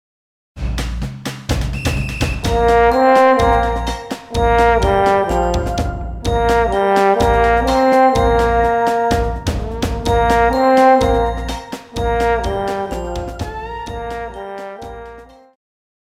Pop
French Horn
Orchestra
Instrumental
World Music,Fusion
Only backing